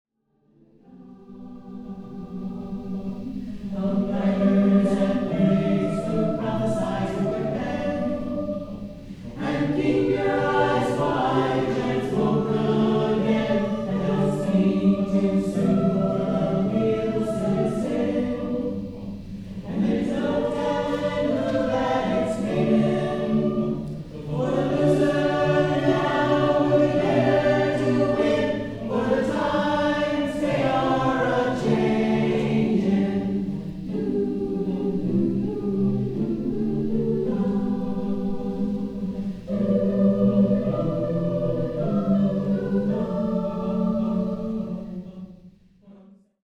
Here is an excerpt of a performance of the piece.)